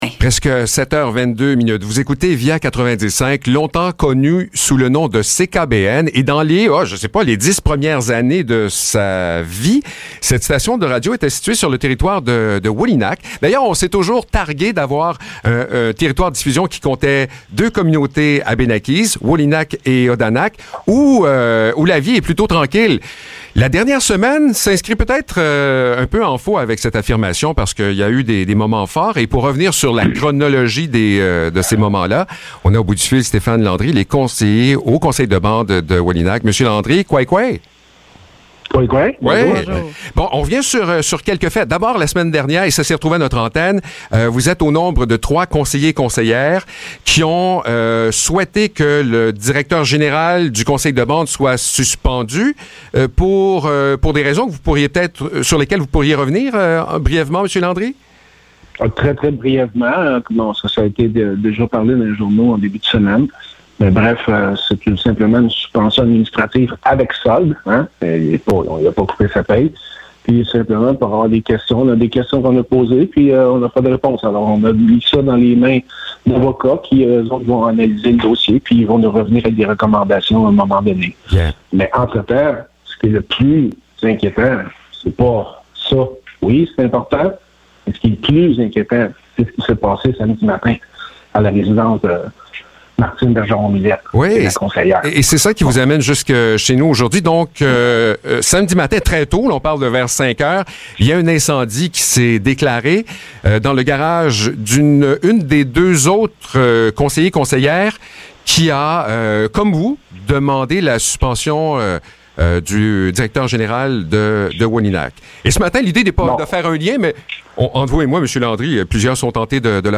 Entrevue avec Stéphane Landry du Conseil de bande de Wôlinak